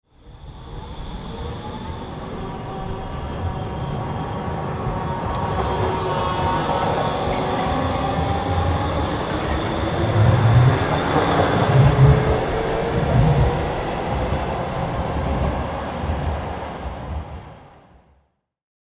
Tram-departing-and-passing-sound-effect.mp3